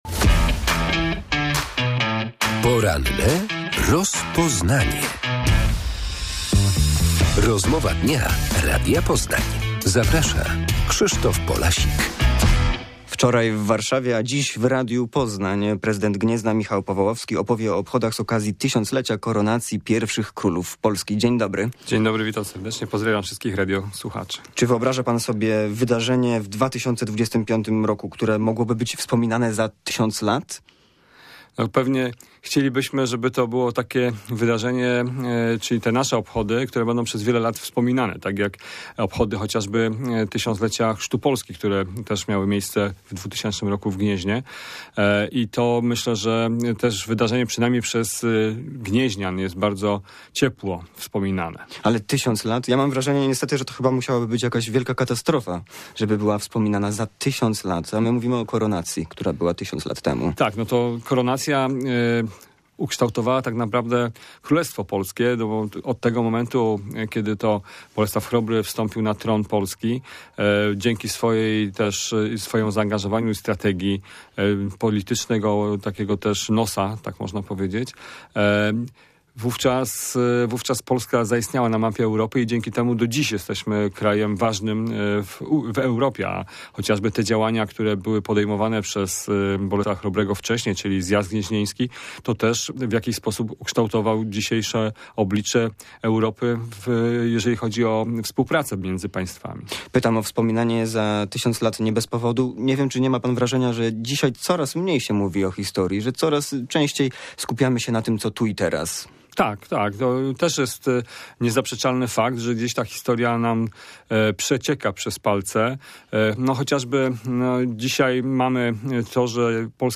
Gościem porannej rozmowy jest prezydent Gniezna Michał Powałowski